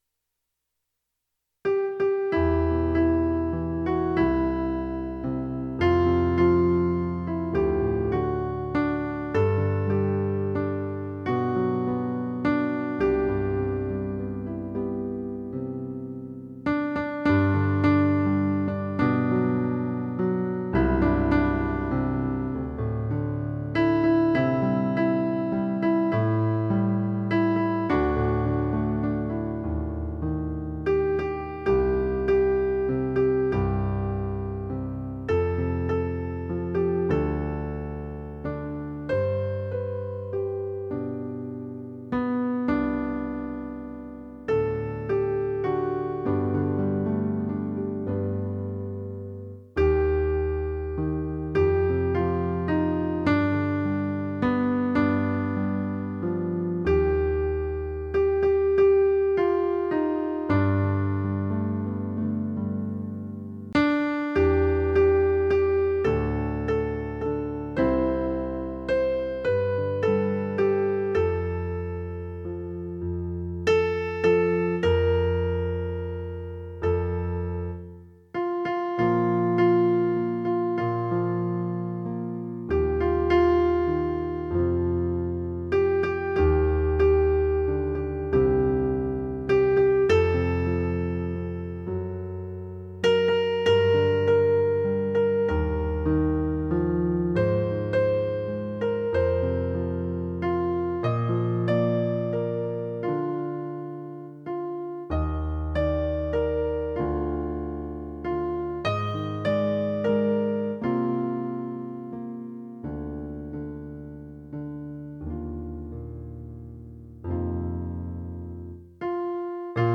Piano & Voice